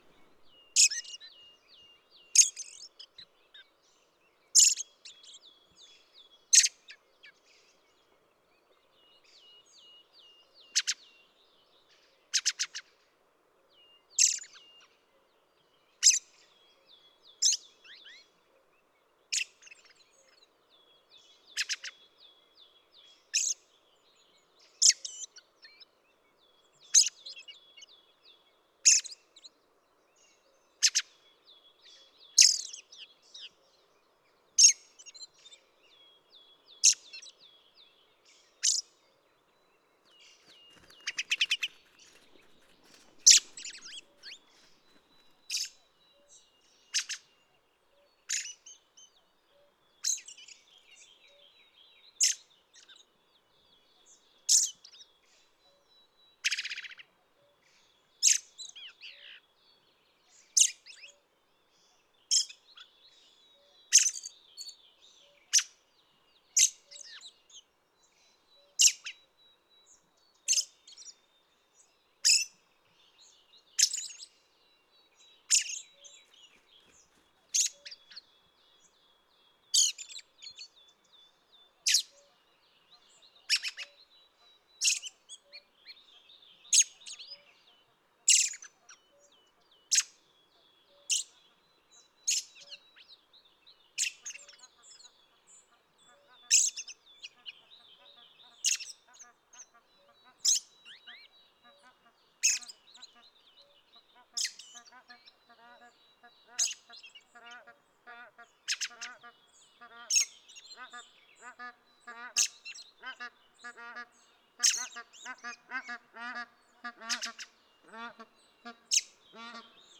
PFR07841-1, 3-01, 130525, Red-throated Thrush Turdus ruficollis, song,
Ider Gol, Mongolia